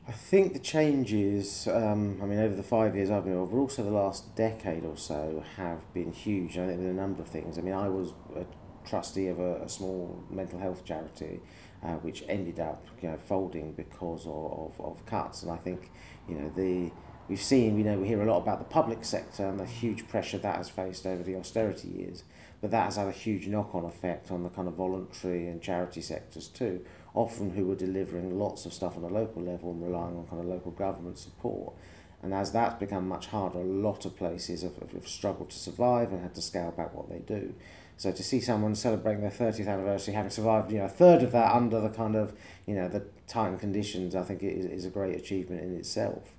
James Asser – Oral History
James-Assser-councilor.wav